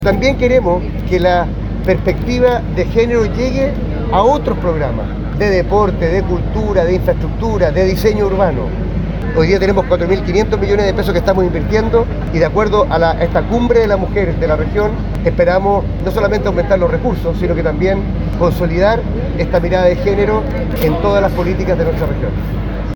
Alcaldesas, diputadas, dirigentas sociales y diversas figuras públicas se dieron encuentro el pasado sábado, a las afueras del Gobierno Regional Metropolitano, para celebrar la primera “Cumbre Regional de Mujeres y Equidad de Género”.
El gobernador y organizador del encuentro, Claudio Orrego, aseguró que no solo se debe buscar reducir el acoso callejero o la promoción del empleo femenino, sino que la idea es ampliar la agenda de género a todos los temas del país.